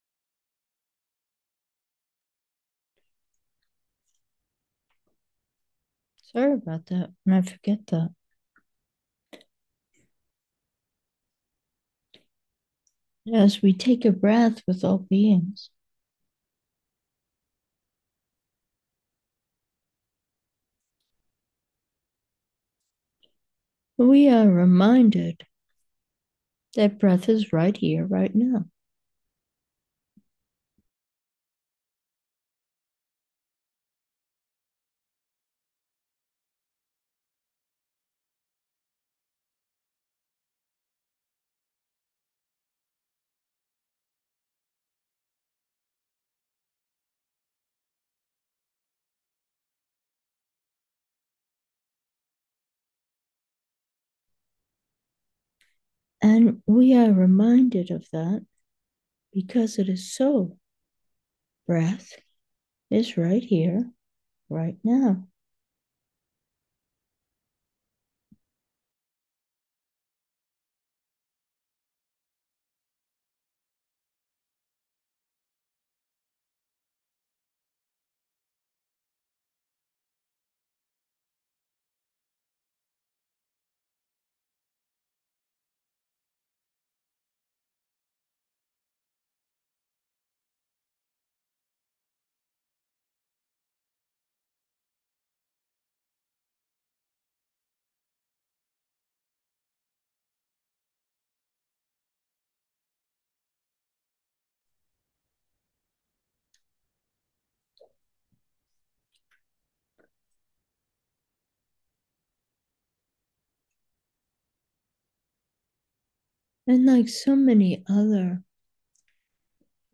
Meditation: here, already